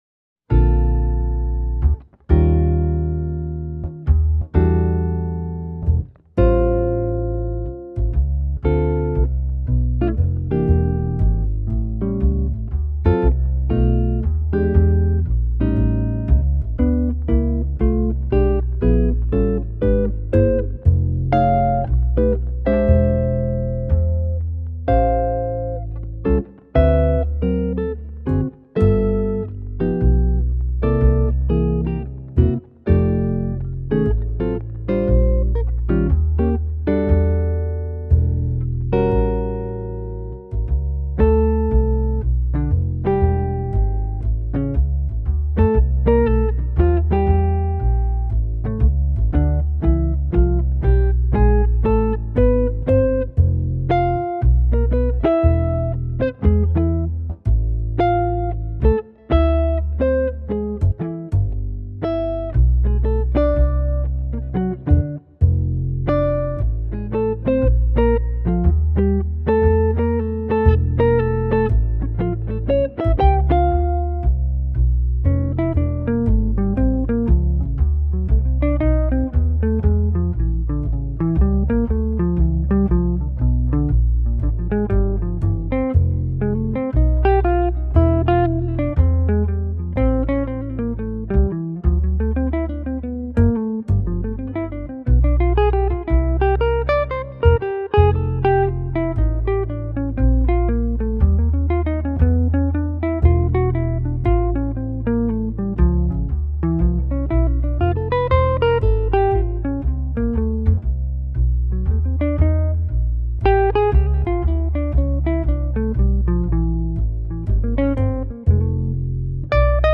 Very nice lines, great approach!
It's kontakt kore player on midi'd bass.